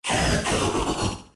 Cri de Zarude dans Pokémon Épée et Bouclier.